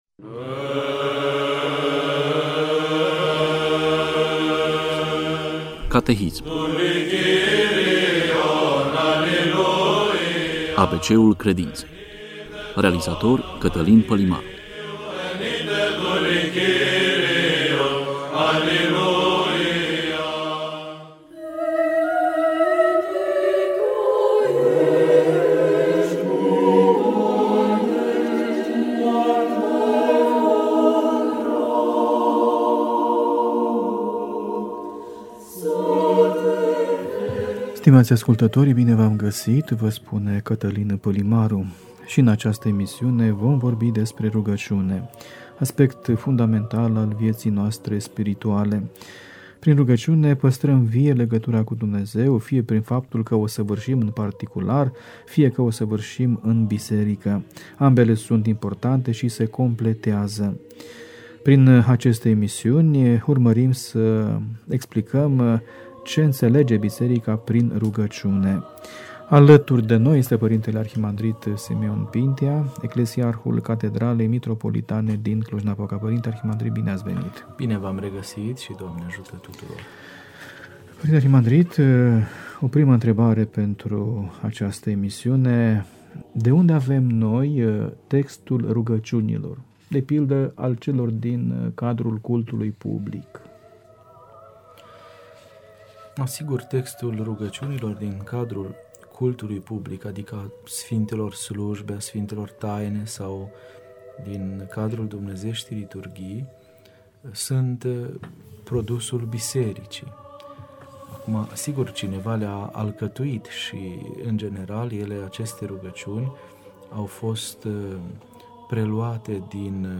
Predică la Sărbătoarea Sfinților Apostoli Petru și Pavel